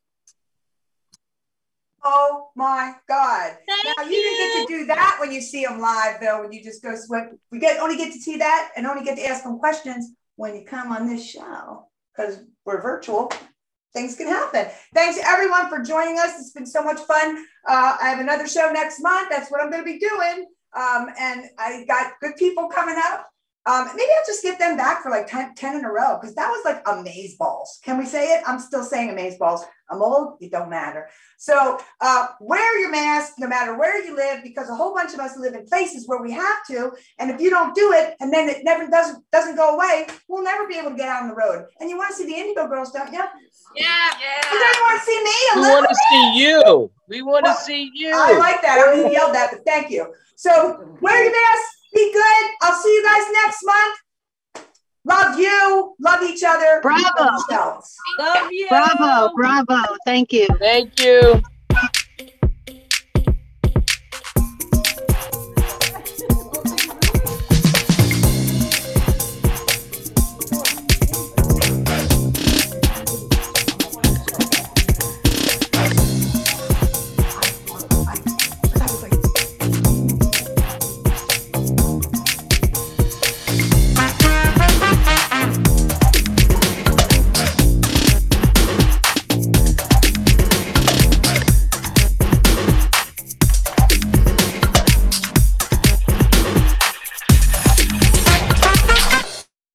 (captured from the zoom livestream)